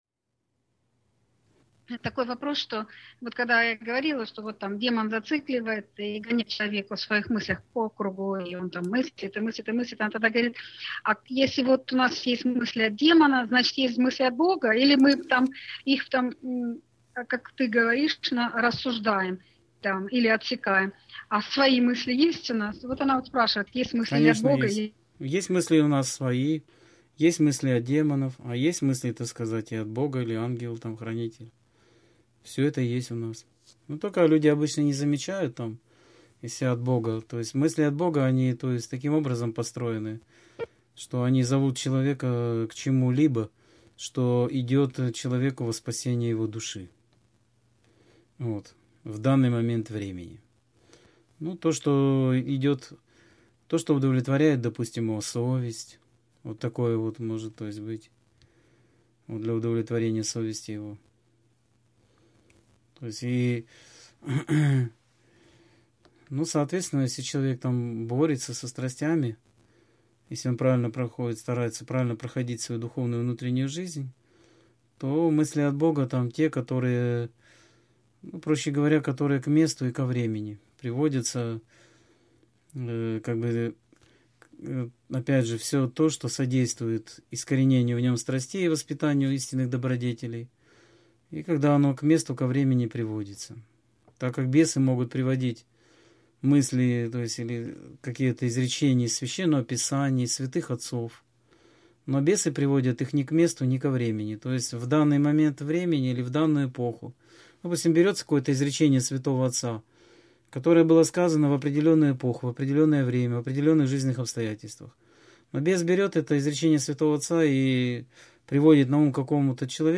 Скайп-беседа 21.11.2015